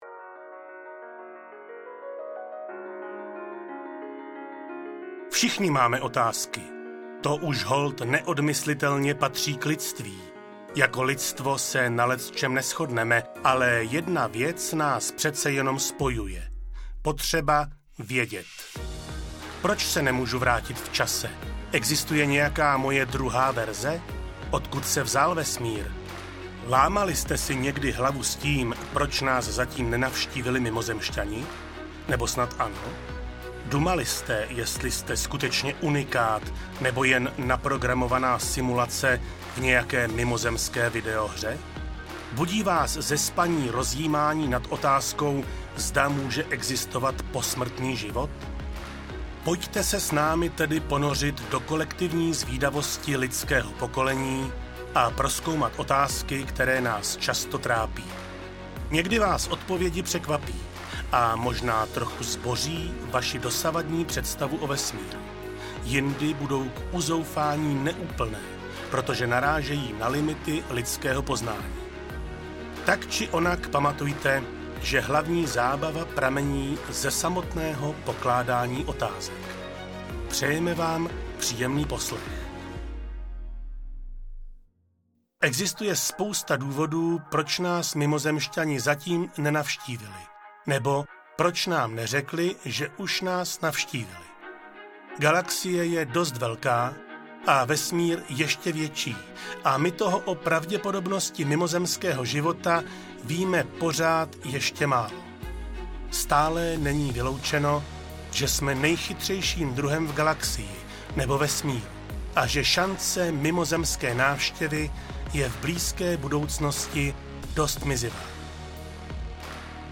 Audio knihaČasto kladené otázky o vesmíru
Ukázka z knihy